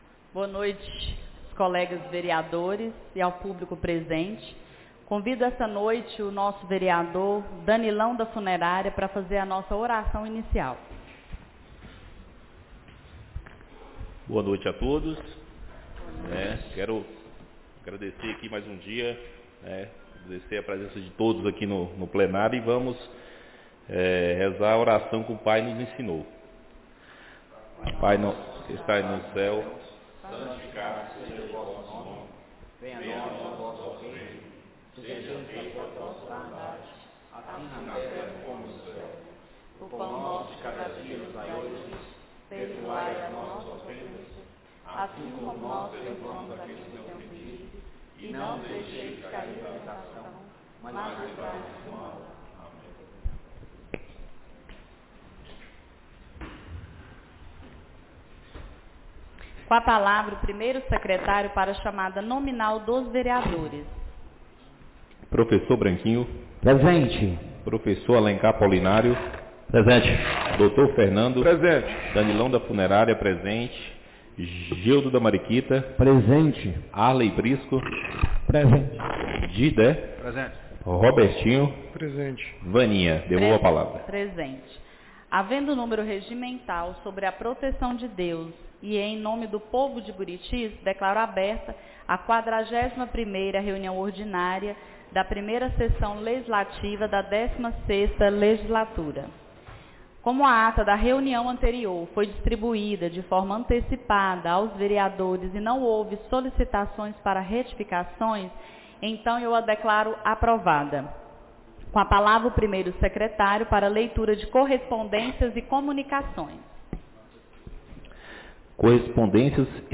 41ª Reunião Ordinária da 1ª Sessão Legislativa da 16ª Legislatura - 24-11-25 — Câmara Municipal de Buritis - MG